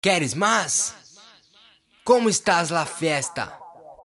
EDITO: Parece brasilero/portugés :l
Sii puede k sea brasileiro o portugues.. tiene acento xD